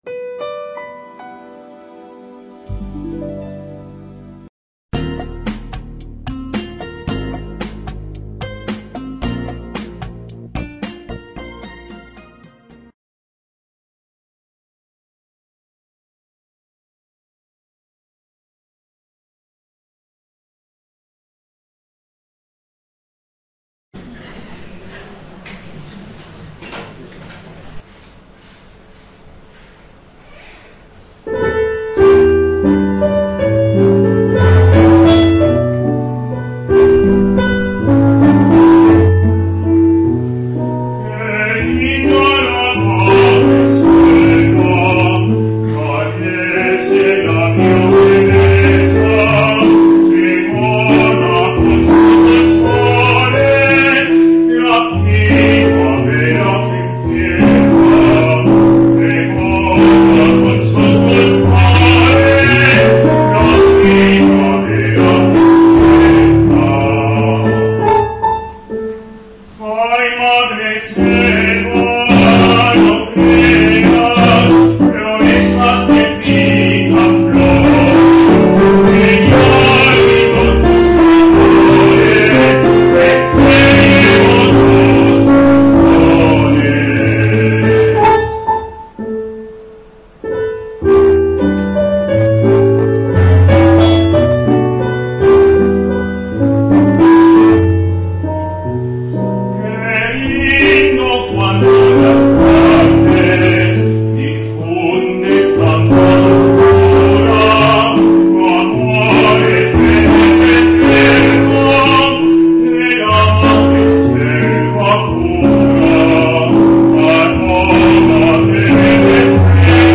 Actuación musical en Acto de Reconocimiento a la…
El Centro Asociado de la UNED “Andrés de Vandelvira” de la provincia de Jaén celebra al final de cada curso académico en su sede de Úbeda un Acto de Reconocimiento a la Comunidad Educativa, en el que se premia la labor llevada a cabo por integrantes de los diferentes estamentos que conforman la comunidad del Centro Asociado. El acto termina con una actuación musical a cargo del dúo coral
tenor
pianista